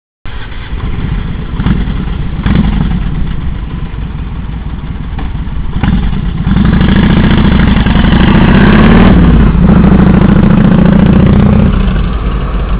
始動〜走行音
muffler721.wav